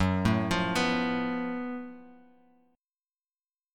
F#mM11 Chord
Listen to F#mM11 strummed